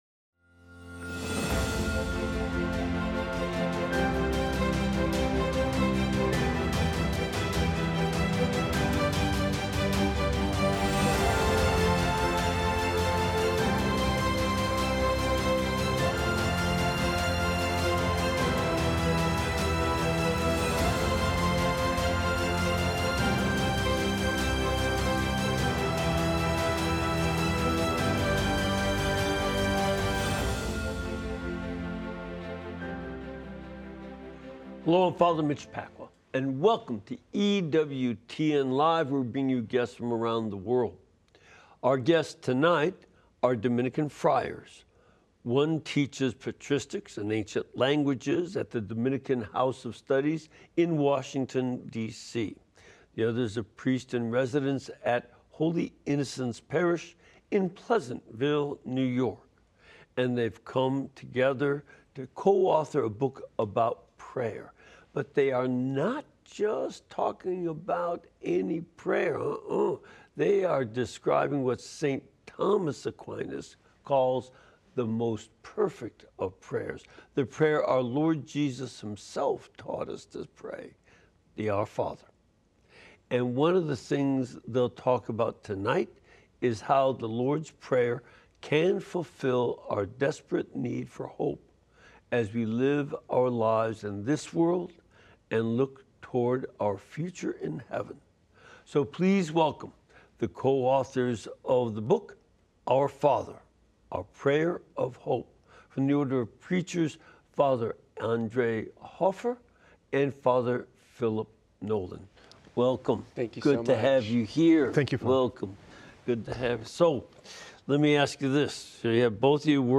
interviews various guests seeking to teach and prepare us for evangelism. Learn more about your faith and reawaken your desire to bring others to the Church.